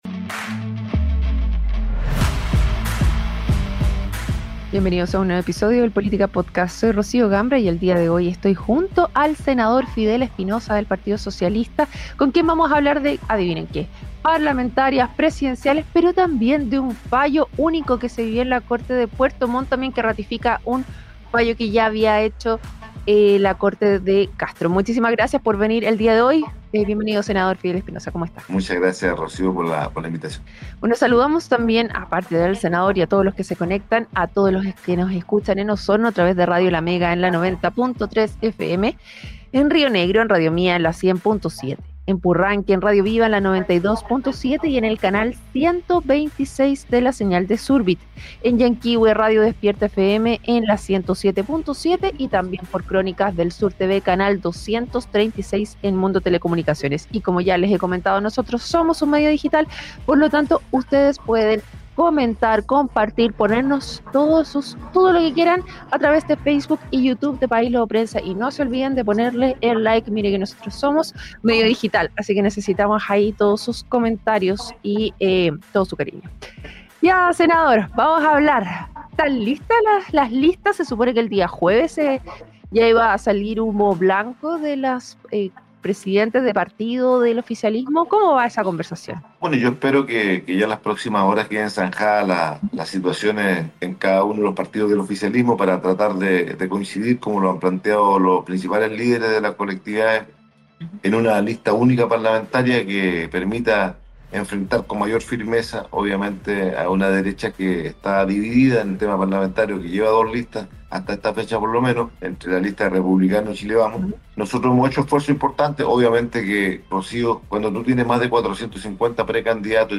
Durante una entrevista en un programa radial, el senador por la Región de Los Lagos , Fidel Espinoza (PS), abordó las negociaciones de las listas parlamentarias del oficialismo, apuntando directamente al diputado democratacristiano Héctor Barría y al Frente Amplio por sus posturas. Además, hizo un llamado a la cautela a los partidos políticos y a resolver sus diferencias para lograr una lista unitaria. En la instancia, también se refirió al caso Fundaciones y a un fallo judicial de la Corte de Apelaciones de Puerto Montt .